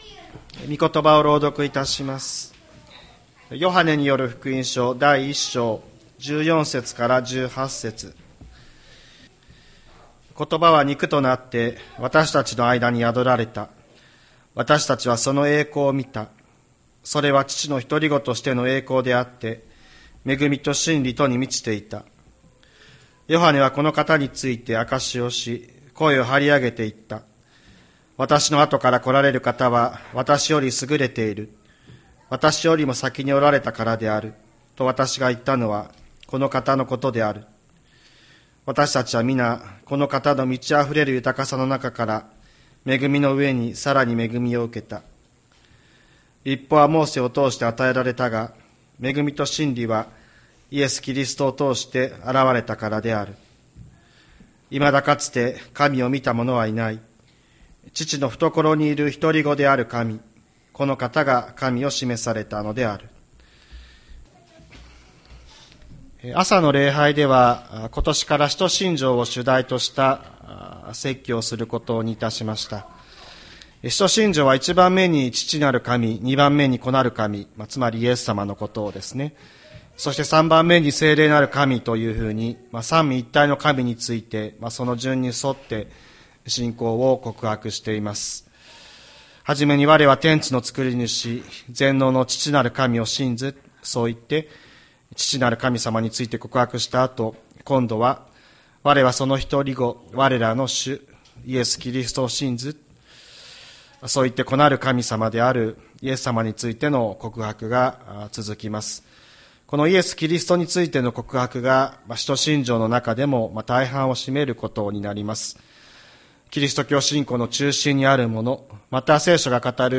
2024年02月04日朝の礼拝「神の見えるところ」吹田市千里山のキリスト教会
千里山教会 2024年02月04日の礼拝メッセージ。